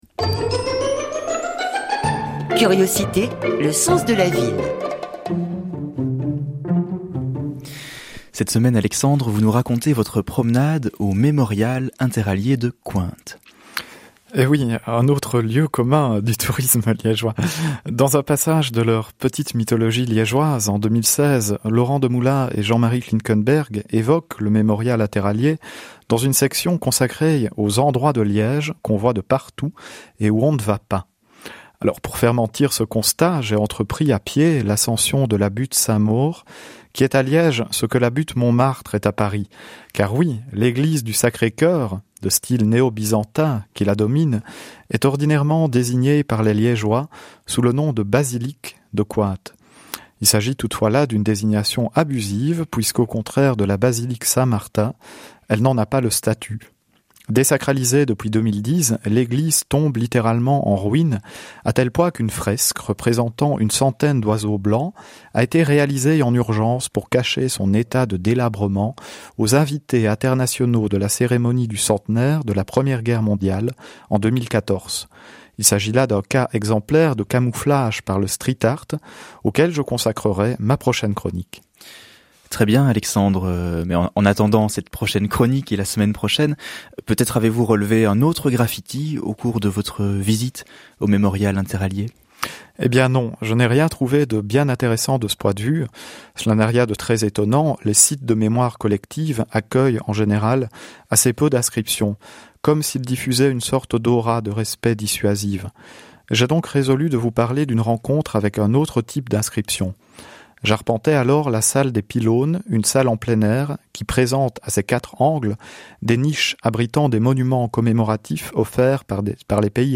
chronique radio